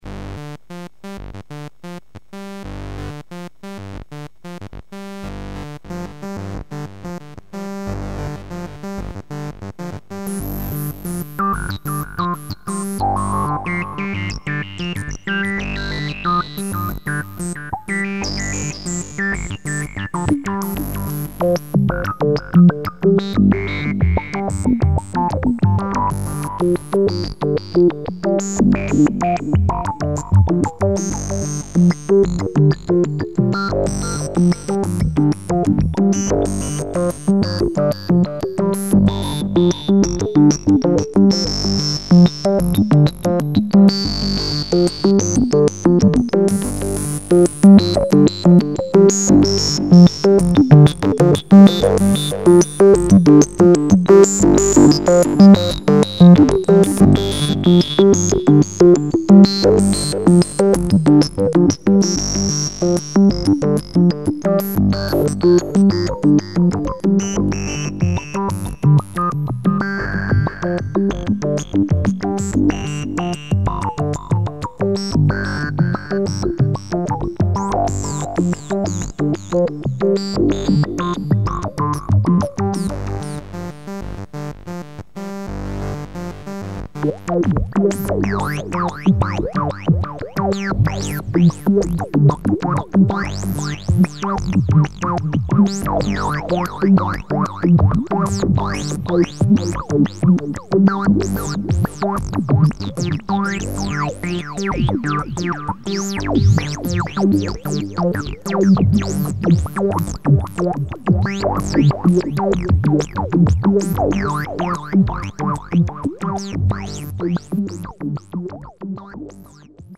5. A simple repeating saw-tooth melody goes into the main input. After the main output comes a little delay & reverb. This turns the focus on the filters in combination with the carefully applied distortion. Also, the melody generates triggers for the envelopes, which modulate the cutoff's beside the LFO.